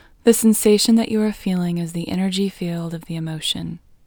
IN – the Second Way – English Female 2